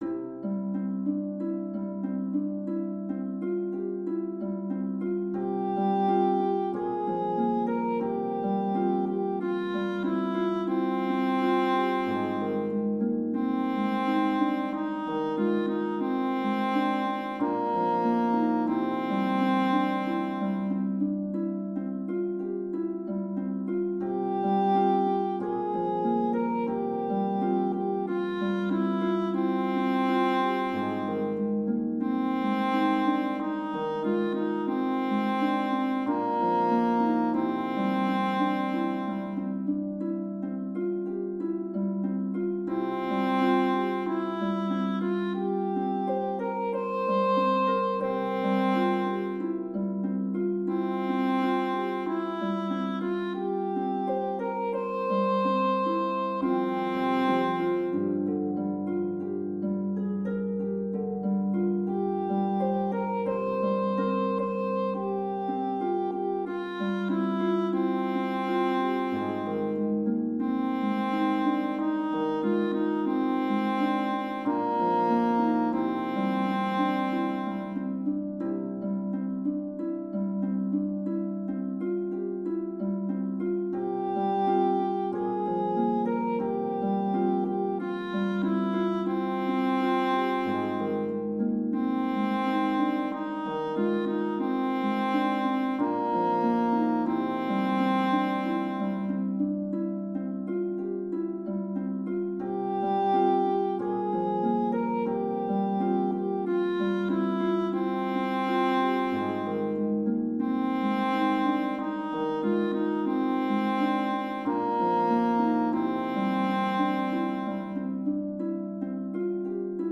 Voicing/Instrumentation: Choir Unison
Piano